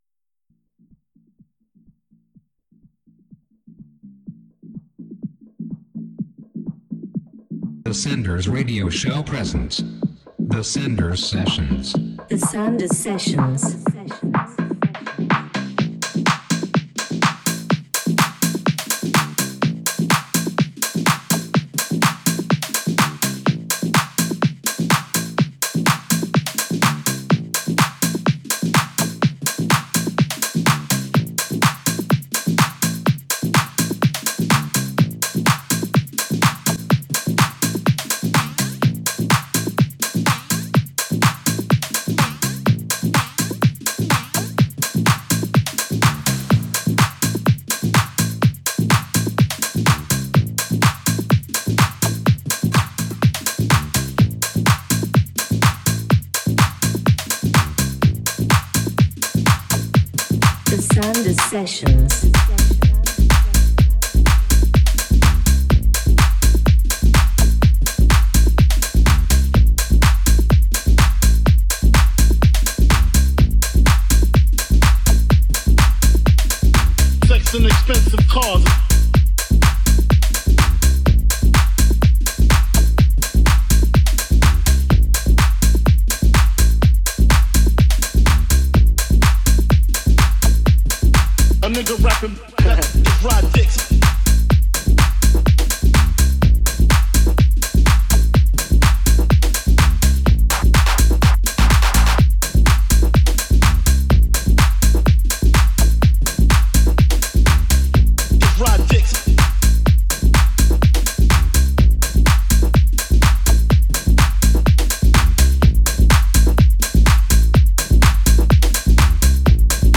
one hour of good sounds mixed